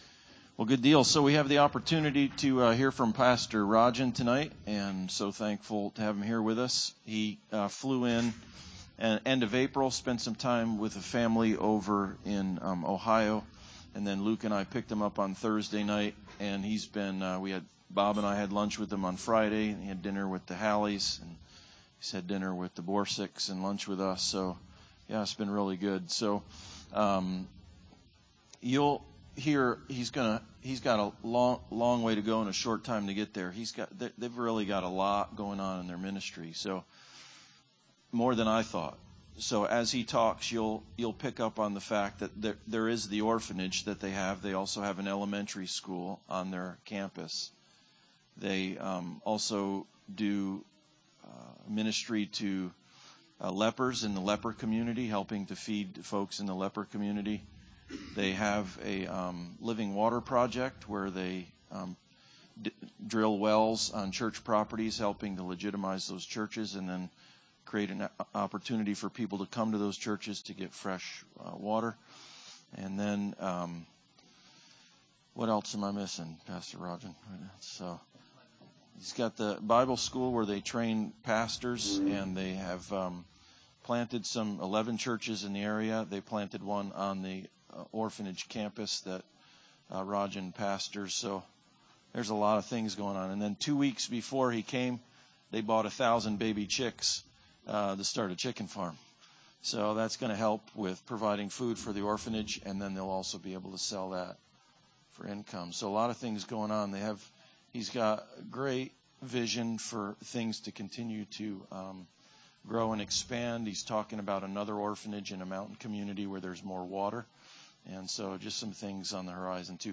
Passage: Luke 5:1-11 Service Type: Sunday Service Bible Text